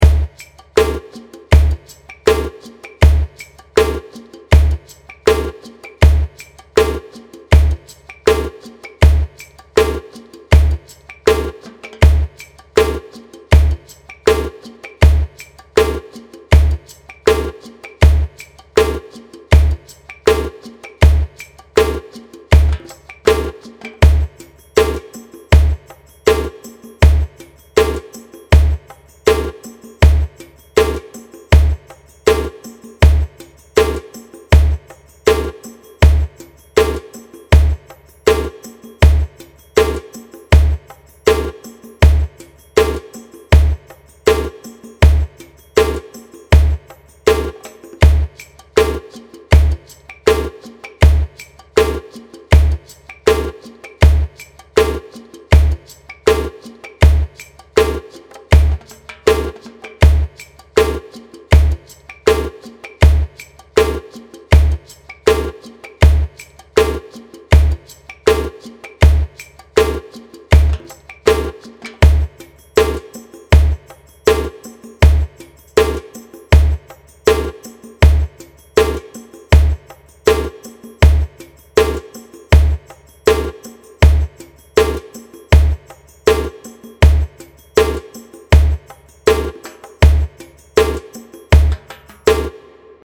Right to Silence - Drum beat